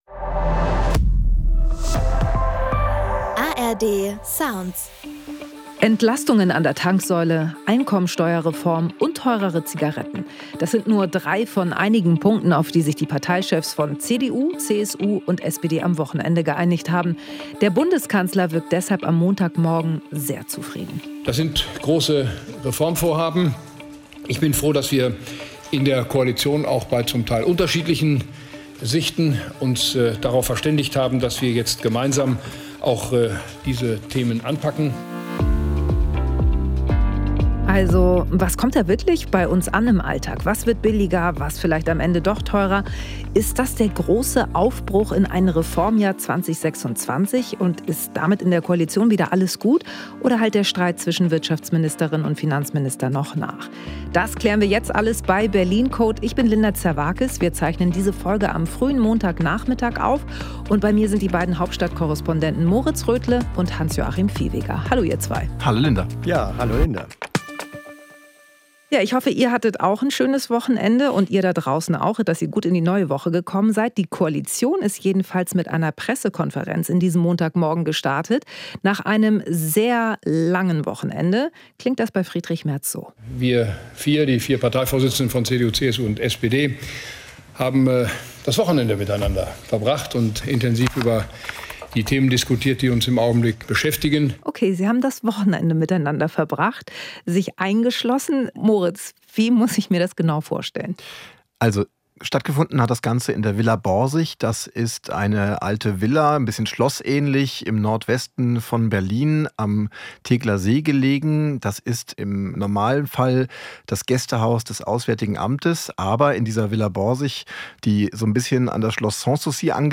Und was kommt noch an Entlastungen, aber auch Belastungen auf uns zu? Darüber spricht Linda Zervakis in dieser Folge Berlin Code mit den ARD-Hauptstadtkorrespondenten